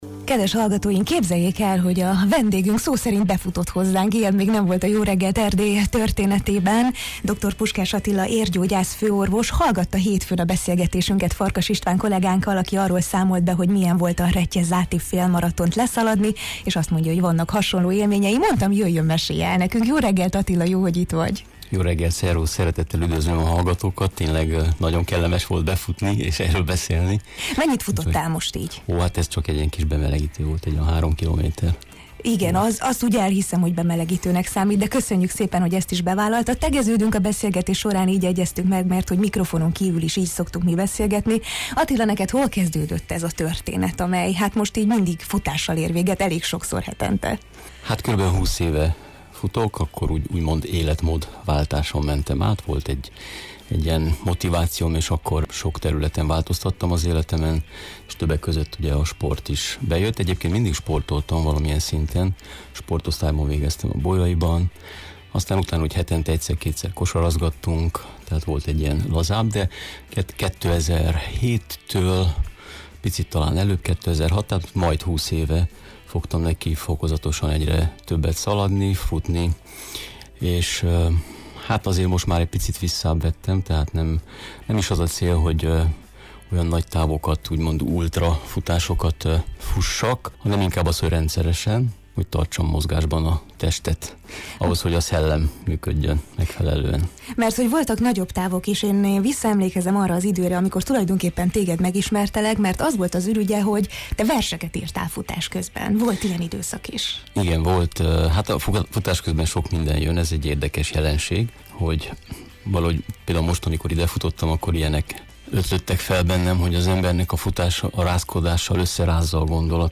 arról beszélgettünk, milyen szerepet játszik a rendszeres mozgás az érrendszer egészséges működésében, és miért is nélkülözhetetlen a mozgás az életünk minden területén